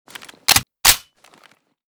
sig550_unjam.ogg.bak